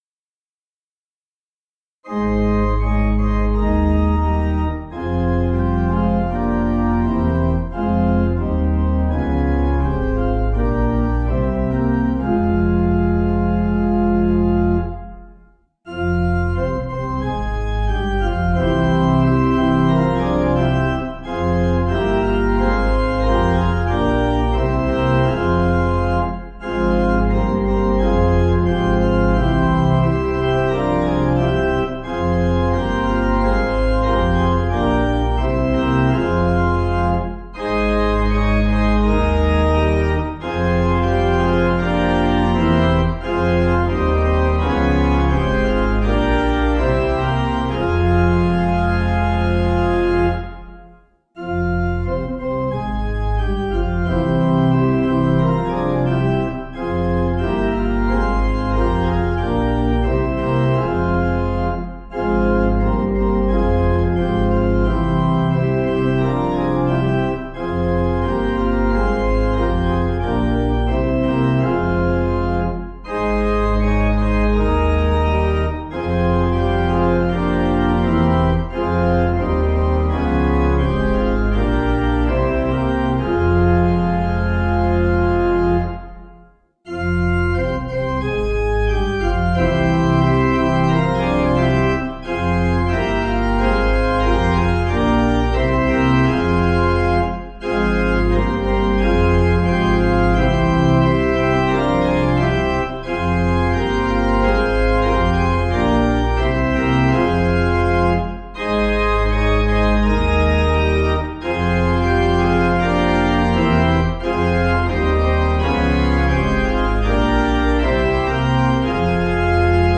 Tune: [The years fly by] (Swedish folk)
Small Church Music #7372